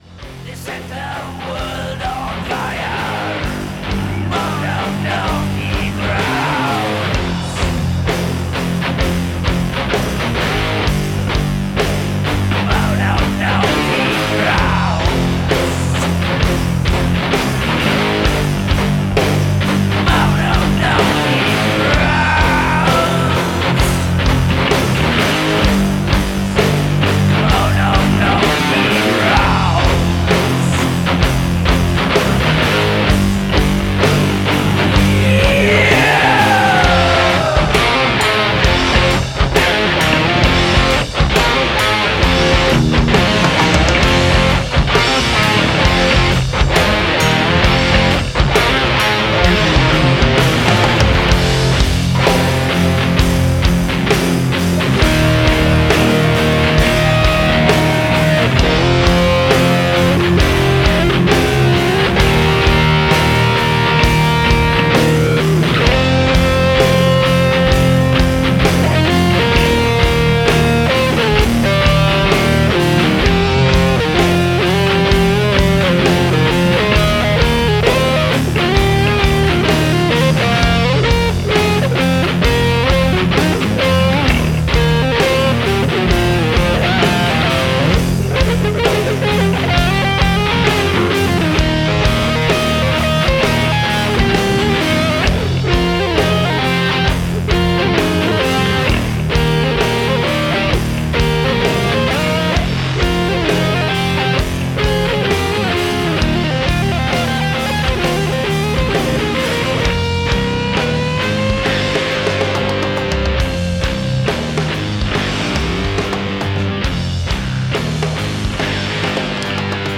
doom metal